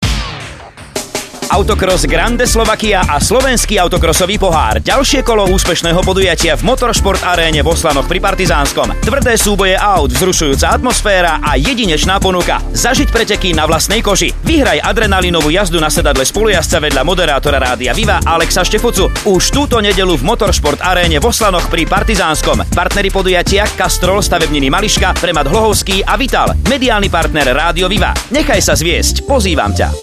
,,ZVUKOVÁ POZVÁNKA RÁDIA VIVA "
spot-viva-preteky-oslany-2011.mp3